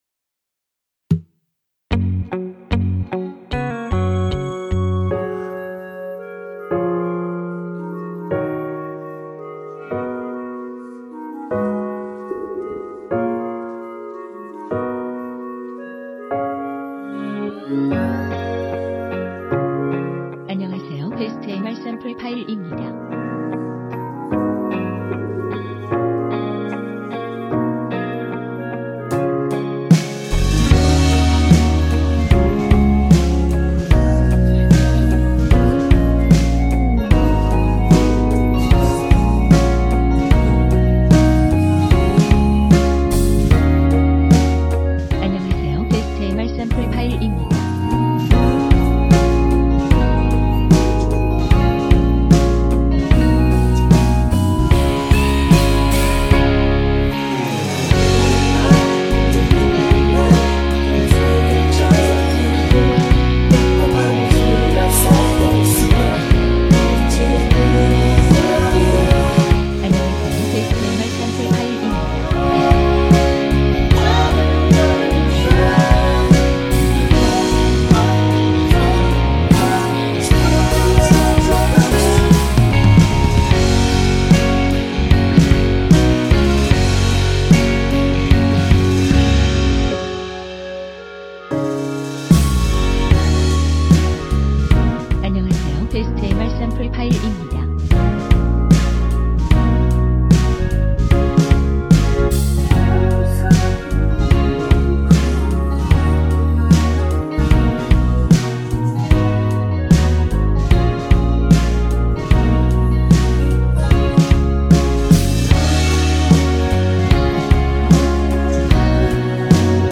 원키 멜로디와 코러스 포함된 MR입니다.(미리듣기 확인)
Gb
앞부분30초, 뒷부분30초씩 편집해서 올려 드리고 있습니다.